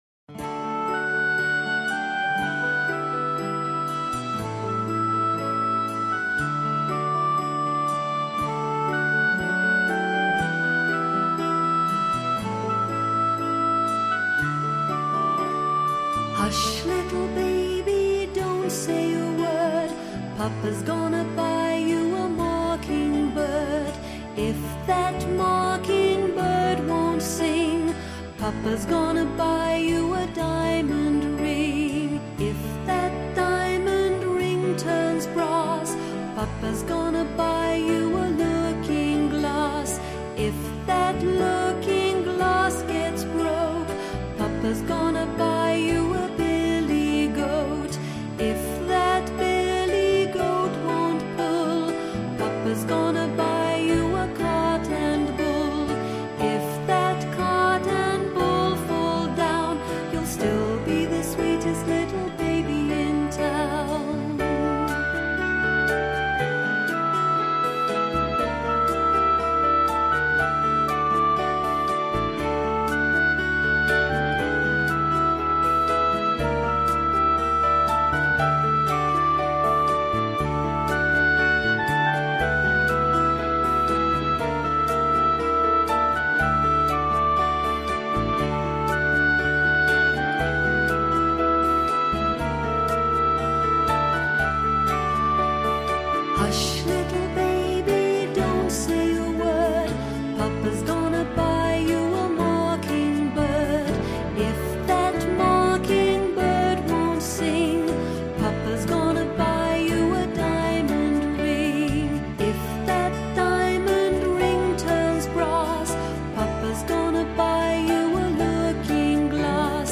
• Категория: Детские песни
Колыбельные на английском / Английские детские песни